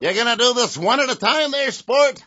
gutterball-3/Gutterball 3/Commentators/Baxter/wack_oneatatimesport.wav at patch-1